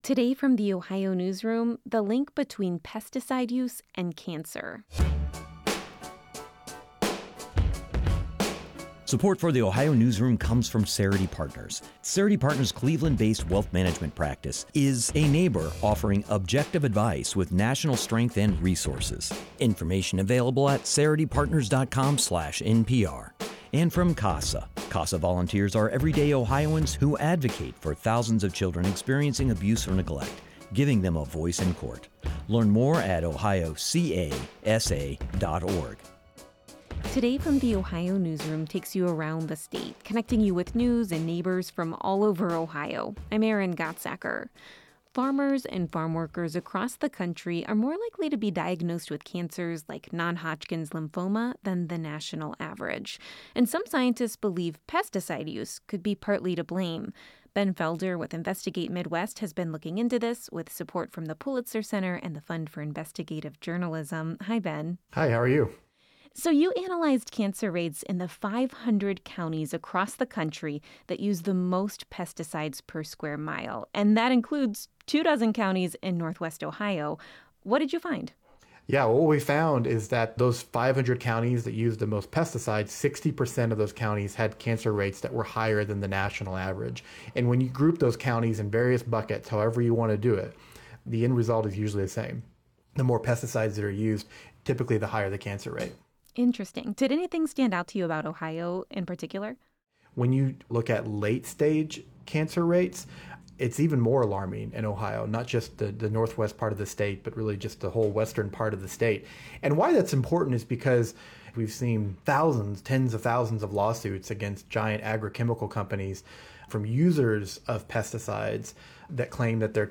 This interview has been lightly edited for clarity and brevity.